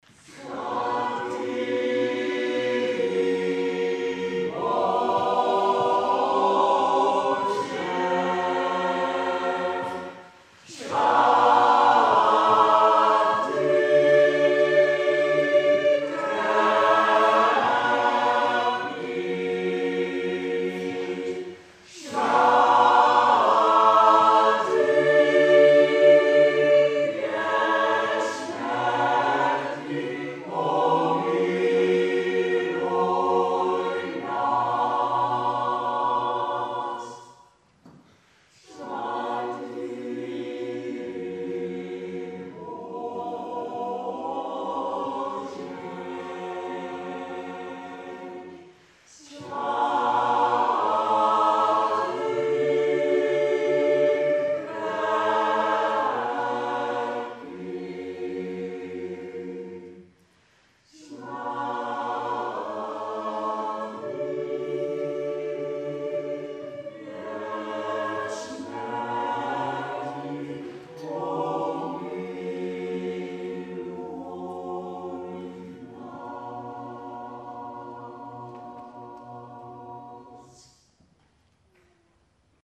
Byzantijns Koor